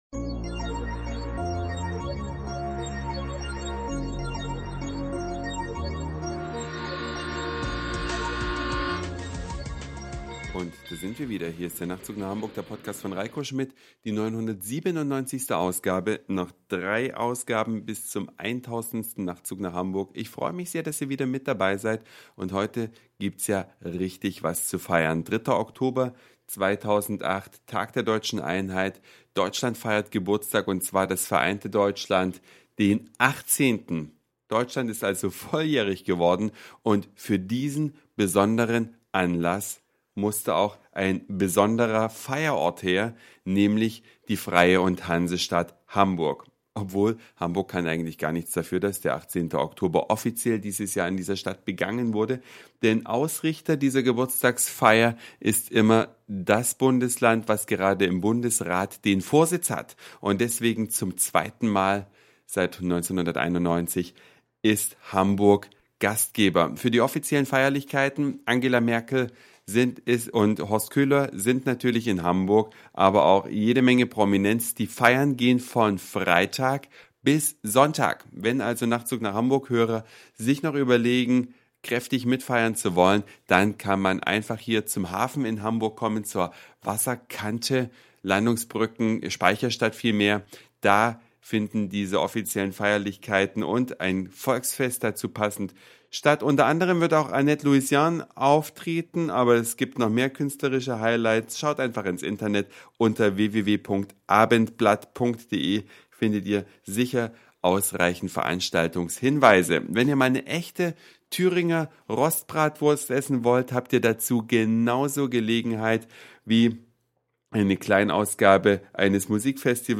Eine Reise durch die Vielfalt aus Satire, Informationen, Soundseeing und Audioblog.
Der 3. Oktober 2008 in Hamburg, Feuerwerk von oben.